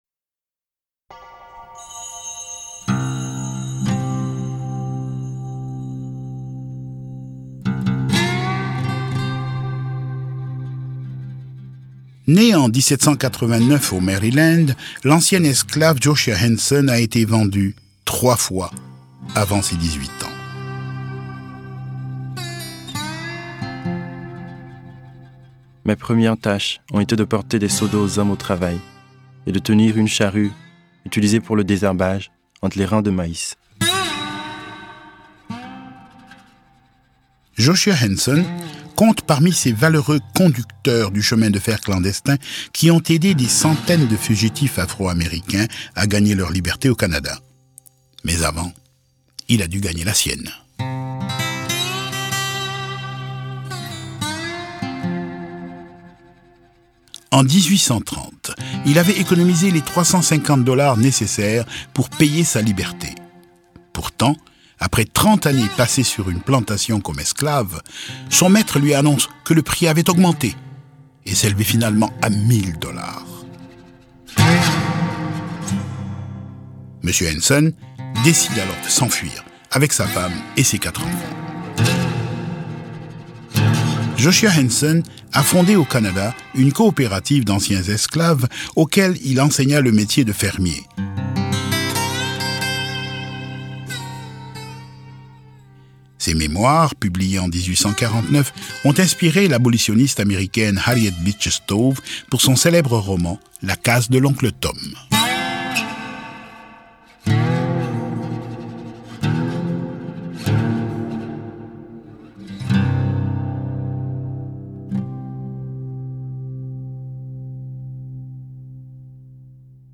Narrateurs: